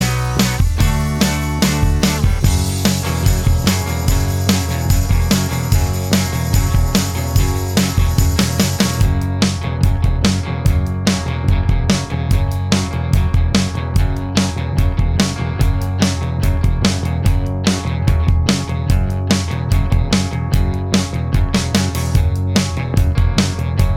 No Lead Guitar Rock 2:17 Buy £1.50